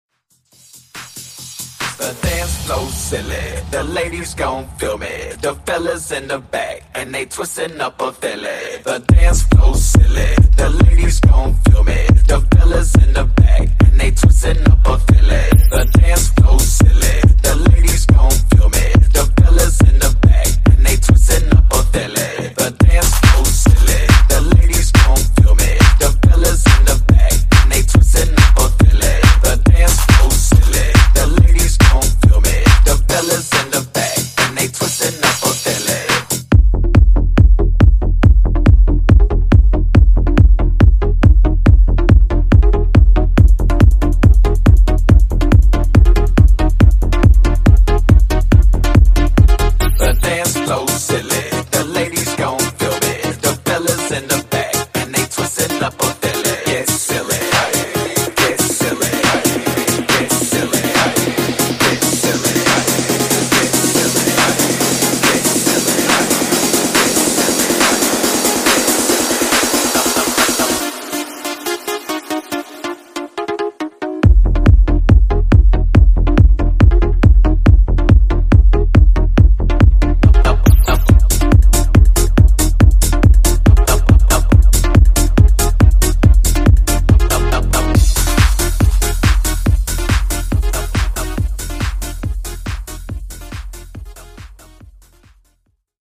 Genre: RE-DRUM
Clean BPM: 86 Time